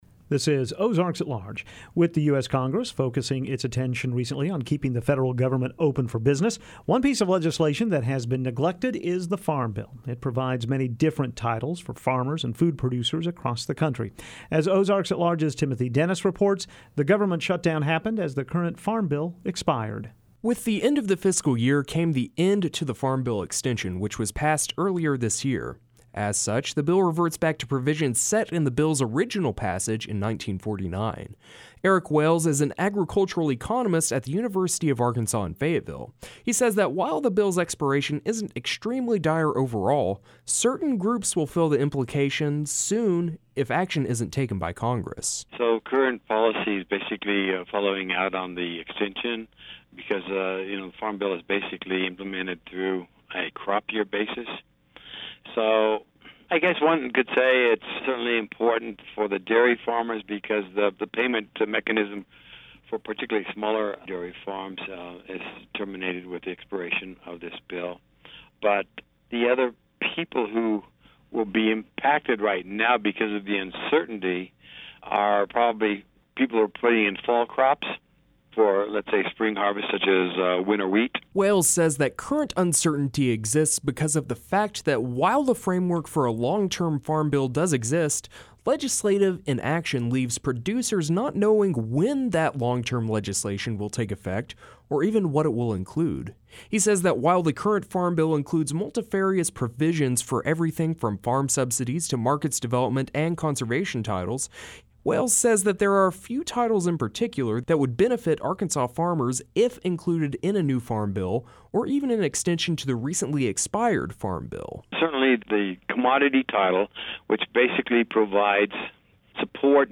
We speak with an agricultural economist to find out what the Farm Bill's reversion to 64-year old policy means for Arkansans.